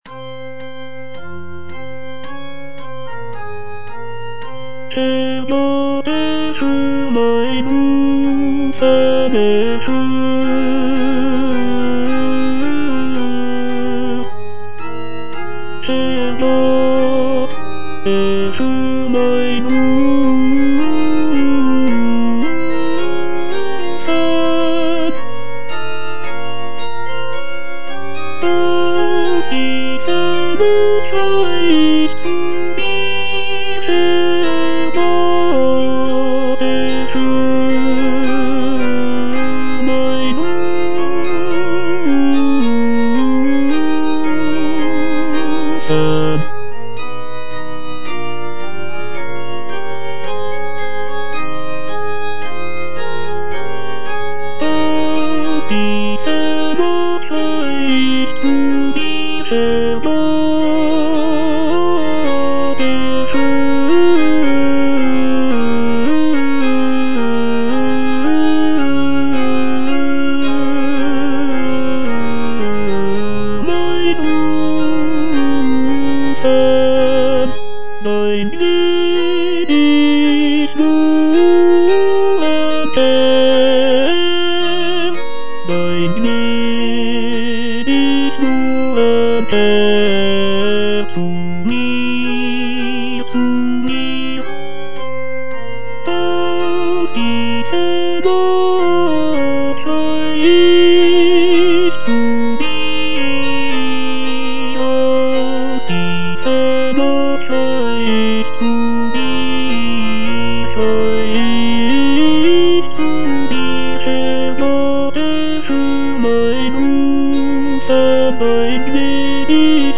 Fuga - Tenor (Voice with metronome) Ads stop: auto-stop
It begins with a somber and introspective mood, gradually building towards a powerful and triumphant conclusion.